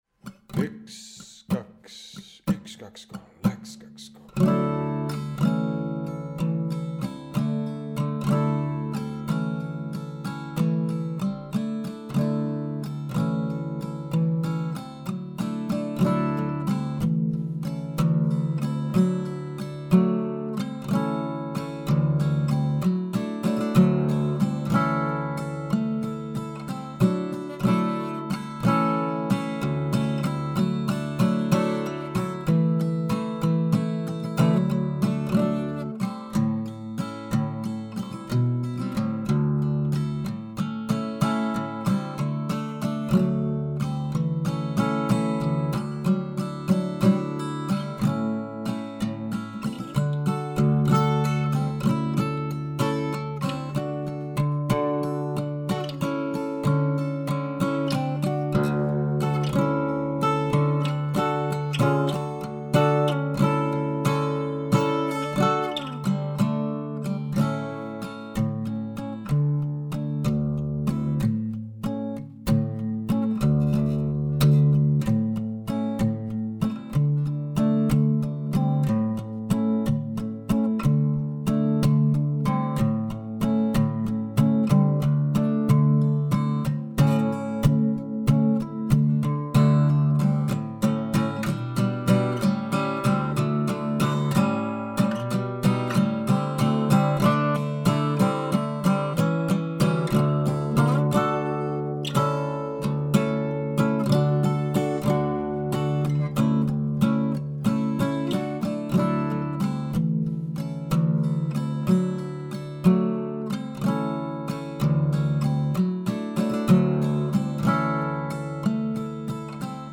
Vana labajalavalts